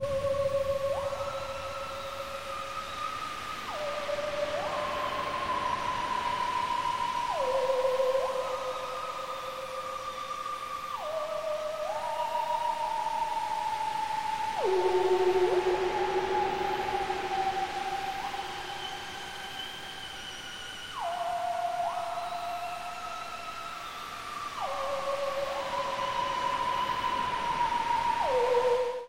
描述：8个无缝小节的令人毛骨悚然的theramin对着粉红色的噪音海浪，就像从一个老的恐怖电影。
Tag: 66 bpm Weird Loops Vocal Loops 4.90 MB wav Key : Unknown